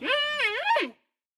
Minecraft Version Minecraft Version latest Latest Release | Latest Snapshot latest / assets / minecraft / sounds / mob / panda / worried / worried6.ogg Compare With Compare With Latest Release | Latest Snapshot